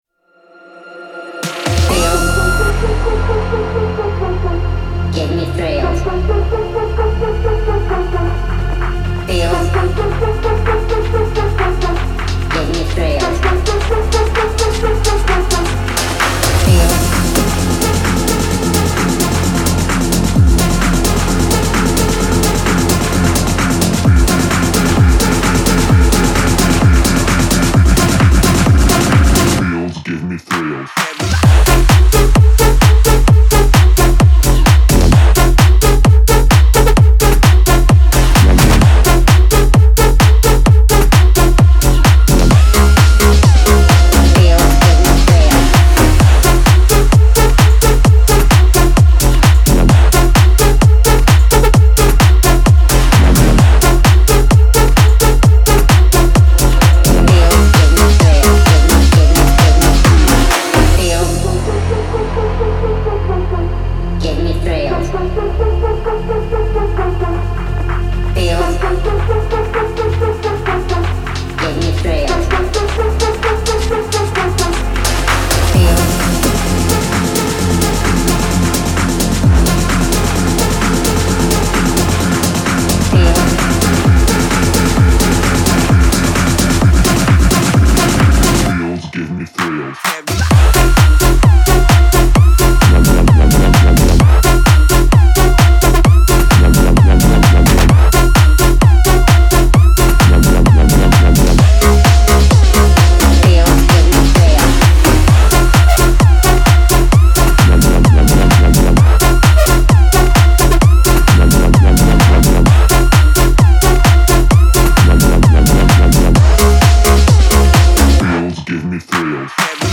الکترونیک
پر‌انرژی